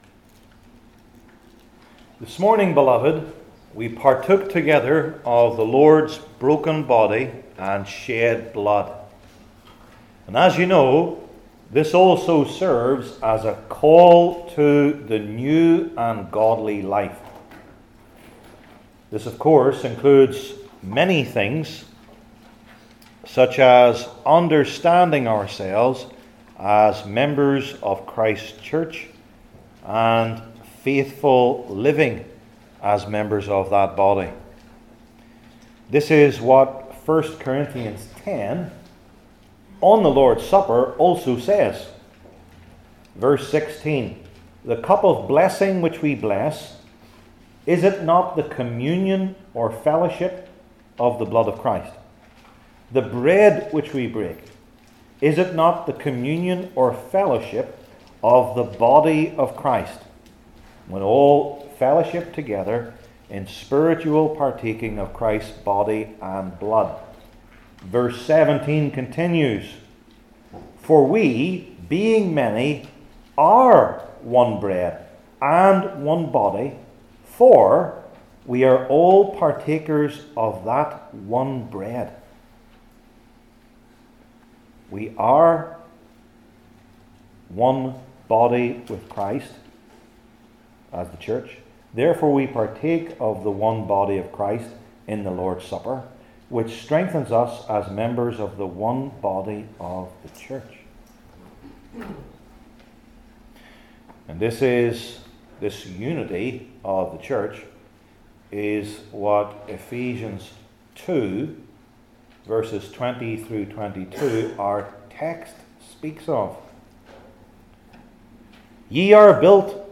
Ephesians 2:20-22 Service Type: New Testament Sermon Series I. The Specific Focus of Our Text II.